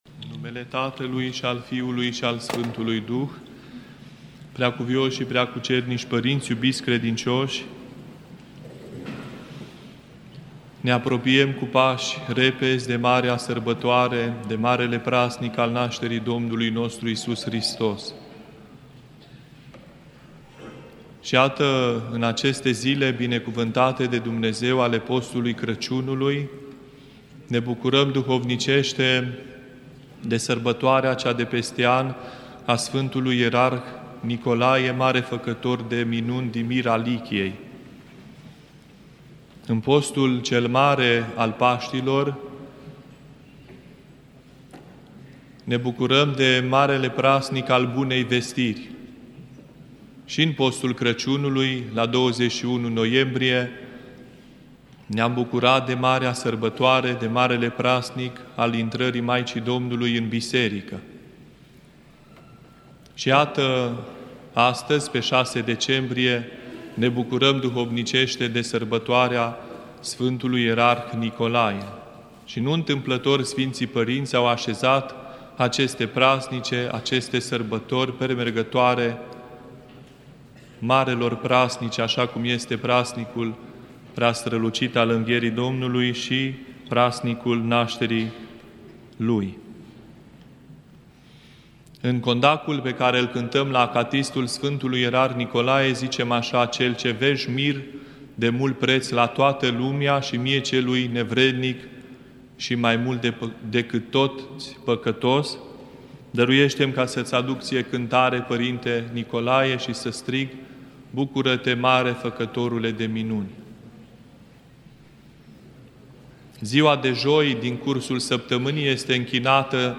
Predică la Sărbătoarea Sfântului Ierarh Nicolae